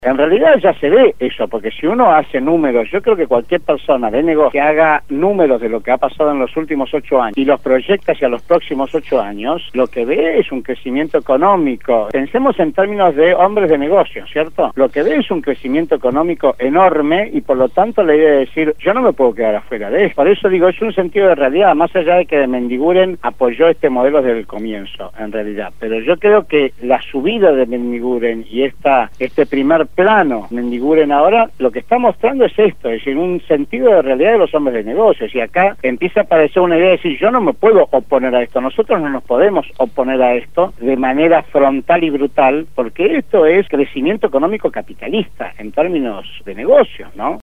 en el programa Punto de Partida de Radio Gráfica FM 89.3